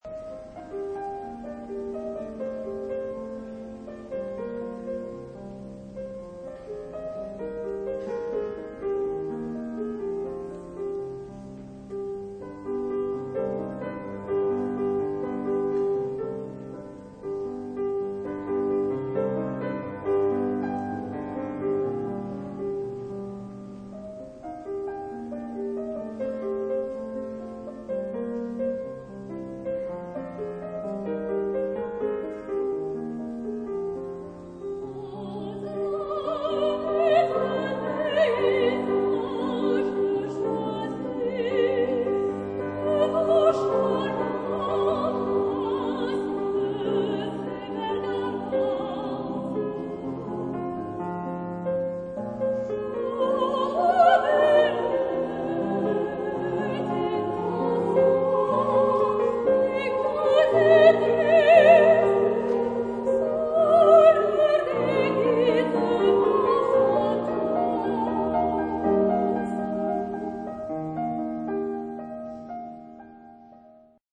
Género/Estilo/Forma: Niños ; Canción ; Profano
Tipo de formación coral: SSA  (3 voces Coro infantil O Coro femenino )
Instrumentos: Piano (1)
Tonalidad : mi bemol mayor